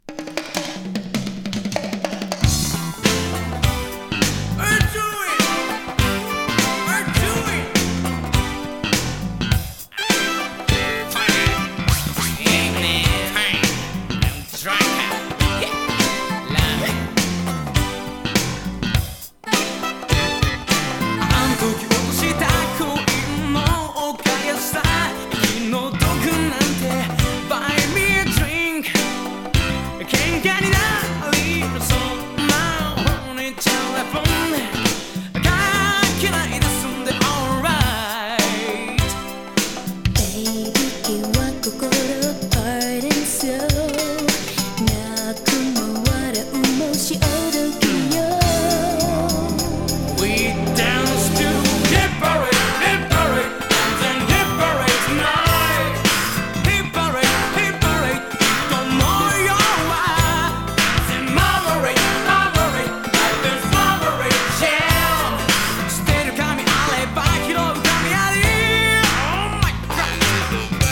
ヴォコーダーも登場します。